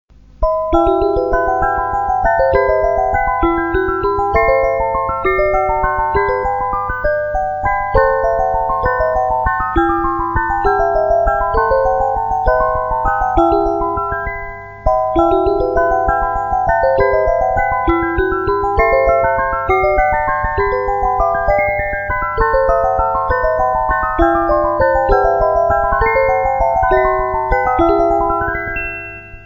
Spielwerk 36stimmig